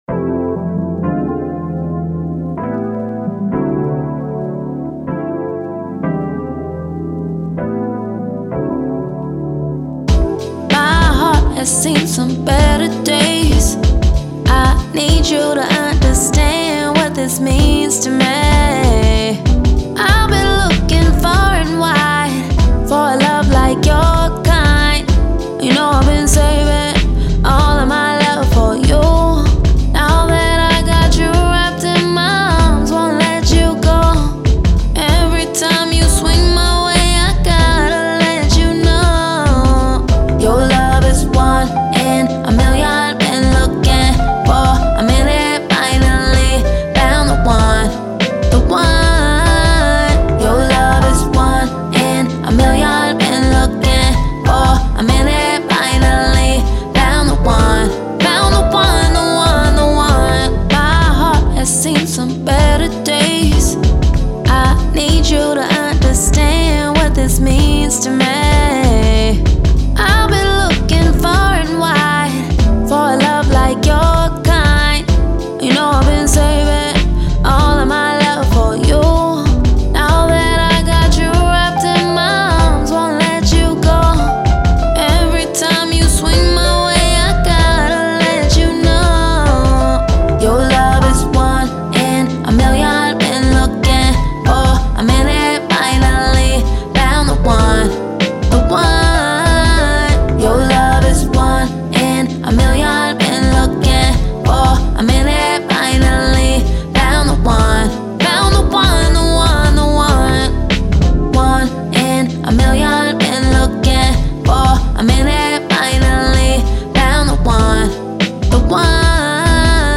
90s, R&B
A Minor